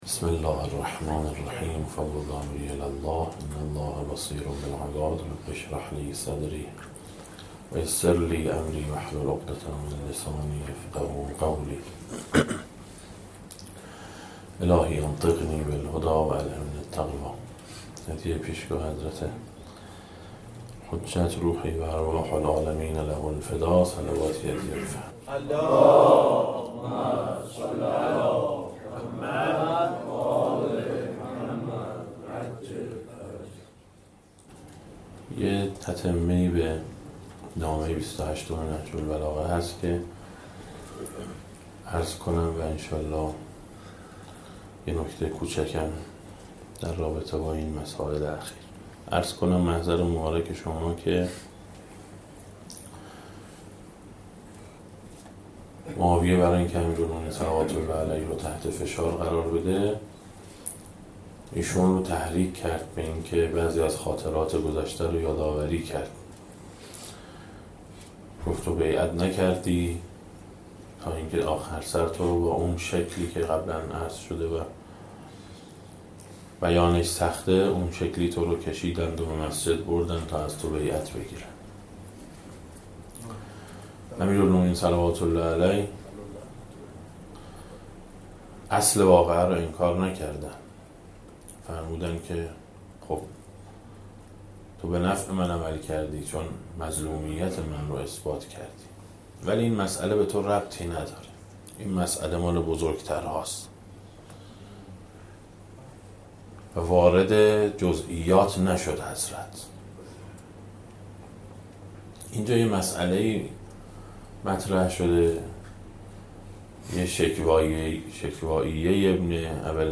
دسته: امیرالمومنین علیه السلام, درآمدی بر نهج البلاغه, سخنرانی ها